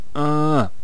archer_die1.wav